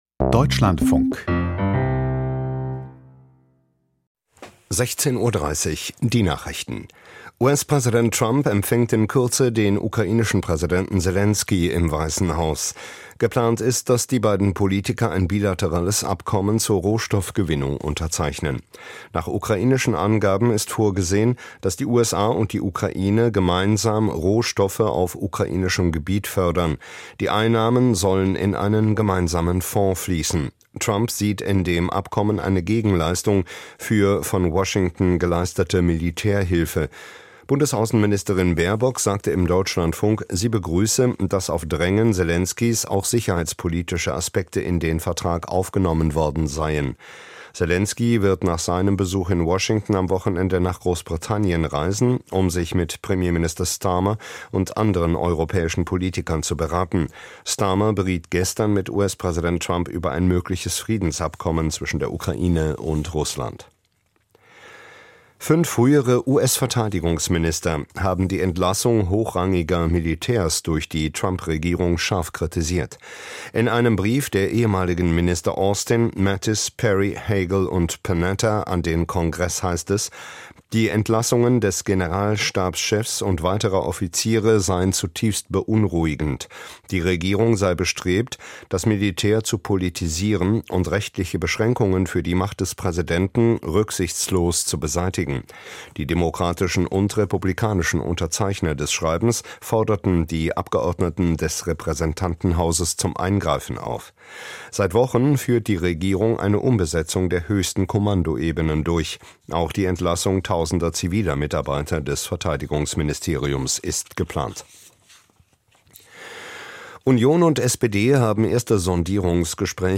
Die Deutschlandfunk-Nachrichten vom 28.02.2025, 16:30 Uhr